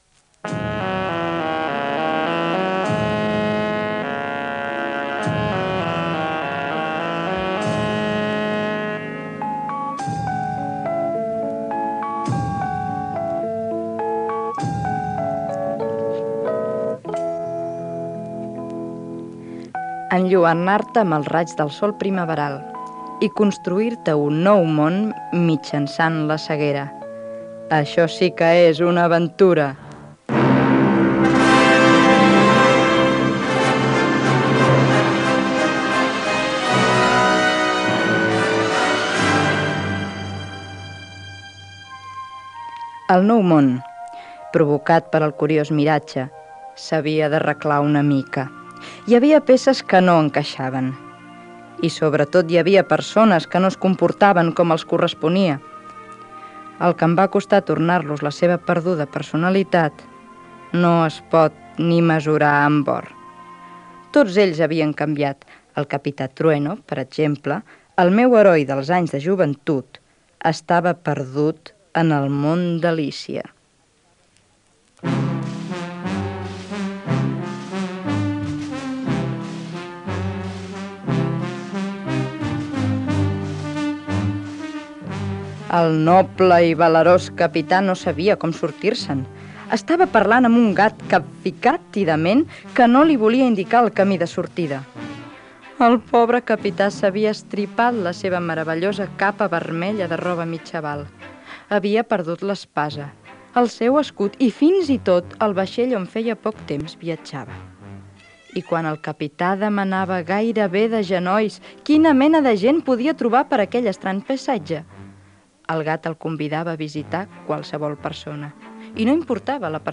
El Capitán Trueno i els seus col.legues Gènere radiofònic Entreteniment